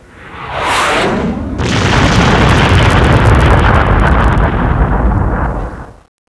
Boom
boom.wav